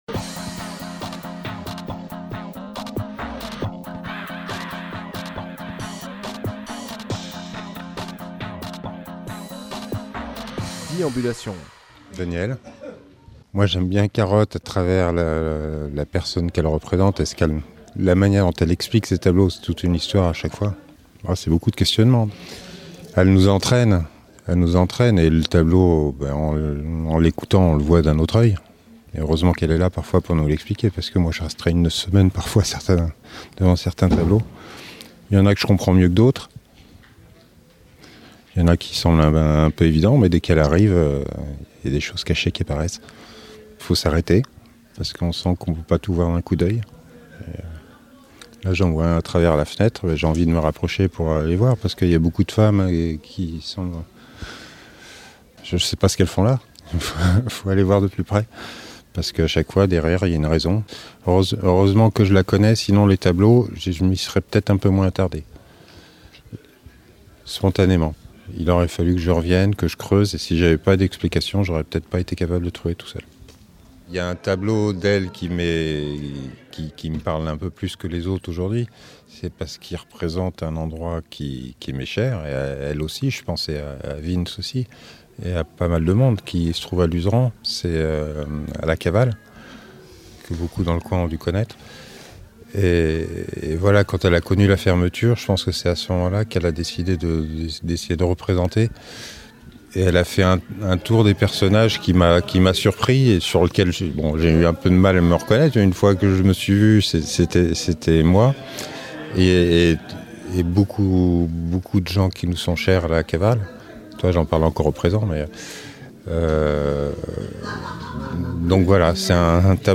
Reportages
Alors j’ai baladé mon micro, voici ce qui s’est dit…
08/03/24 Lieu : Barnave Durée